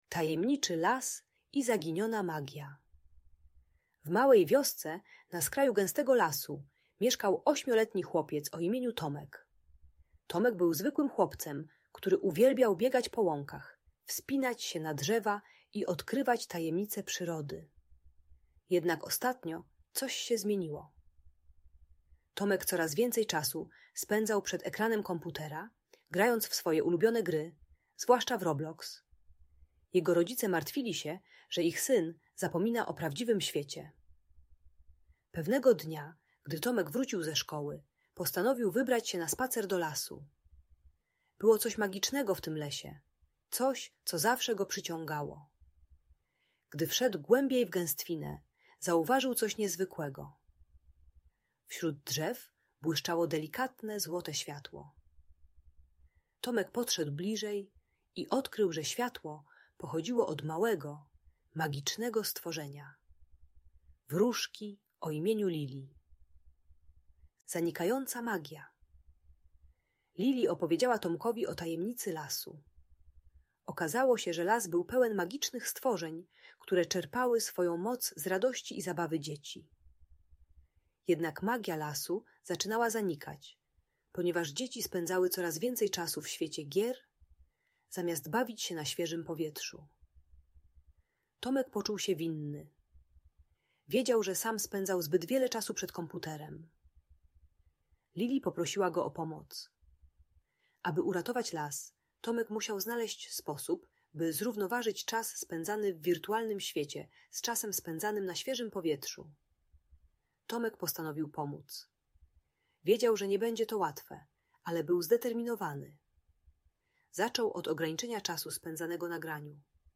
Bajka dla dziecka które za dużo gra na komputerze lub telefonie, przeznaczona dla dzieci 6-9 lat. Audiobajka o uzależnieniu od ekranów i gier (Roblox) uczy techniki równoważenia czasu ekranowego z zabawą na świeżym powietrzu. Pomaga dziecku zrozumieć wartość prawdziwego życia i relacji z przyjaciółmi.